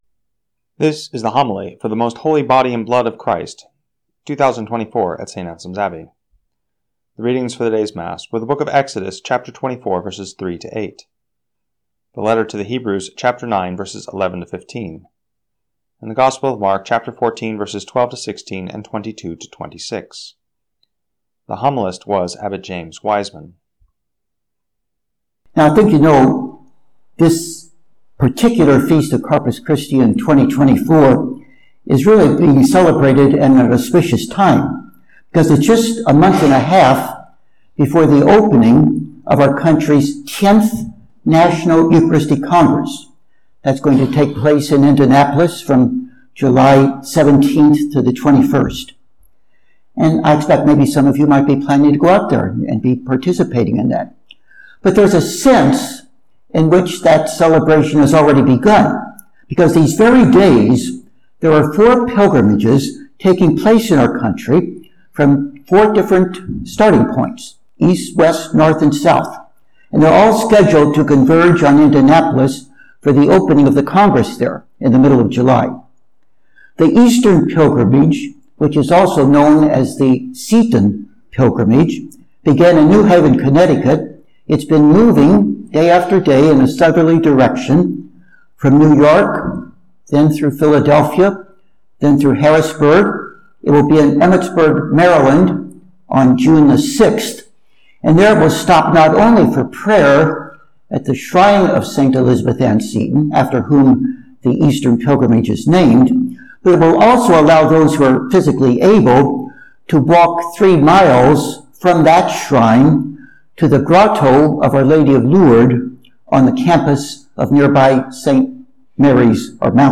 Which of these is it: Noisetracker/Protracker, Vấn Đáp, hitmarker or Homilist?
Homilist